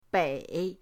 bei3.mp3